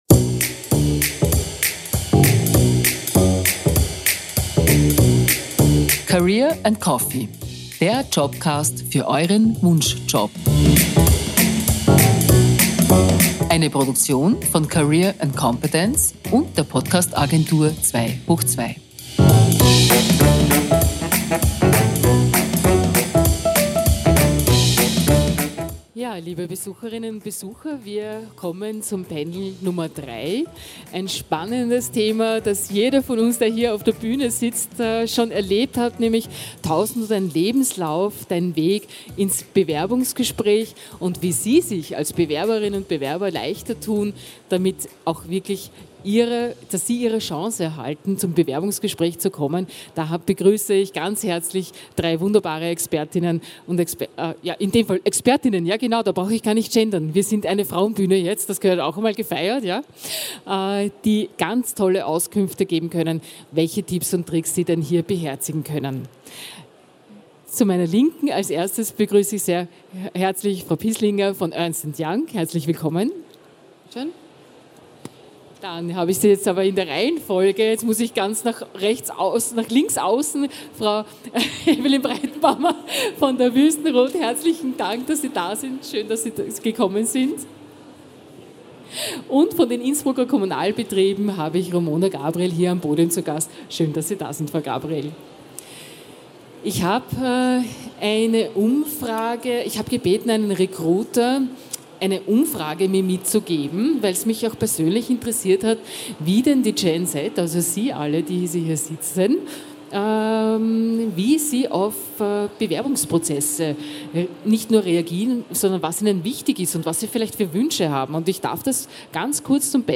Livemitschnitt von der career & competence 2023 in Innsbruck, am 26. April 2023.